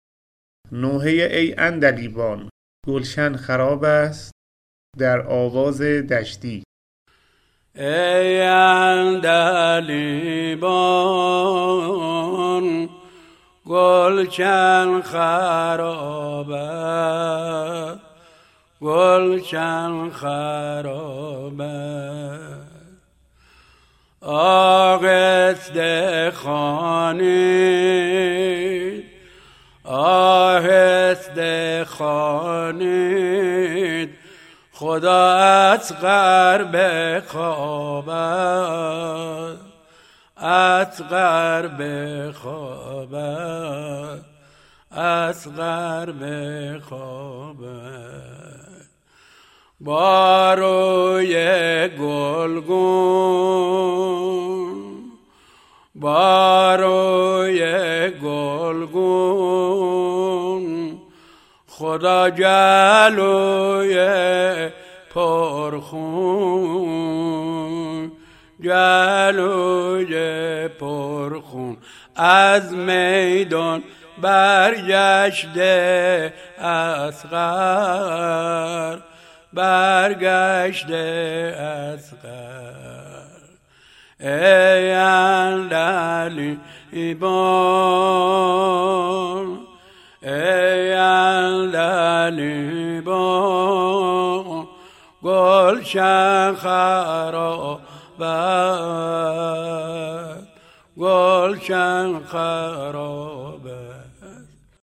نوحه‌خوانی
آواز دشتی: این نوحه که دارای شکل دو قسمتی است، در مجالس تعزیه اغلب مناطق ایران با ضرب آهنگی متفاوت نیز در گوشه درآمد خوانده می‌شود که بخشی از آن به صورت نوحه‌های یکی‌سه‌تایی خوانده شده است: